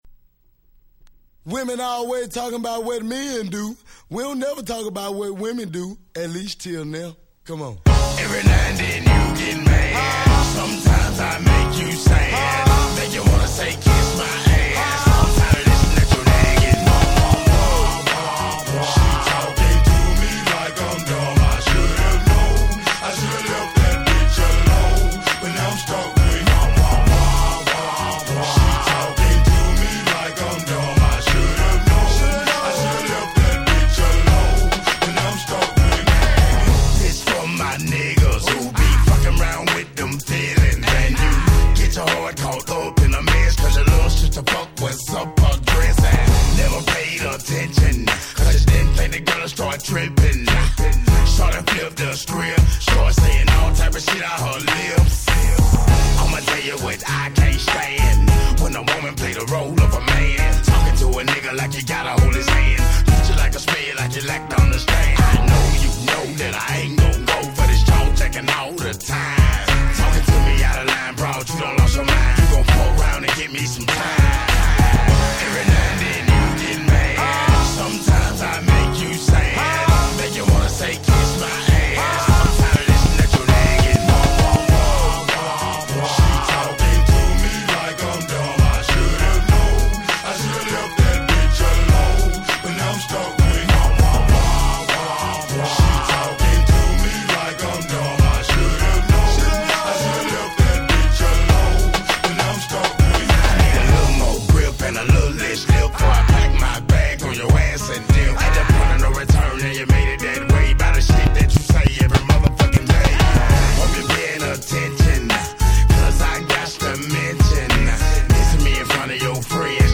03' Smash Hit Southern Hip Hop !!
大合唱必至のキャッチーなサビで大ヒット！
今でもバッチリ機能するフロアクラシックです！